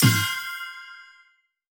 soft-spinnerbonus.wav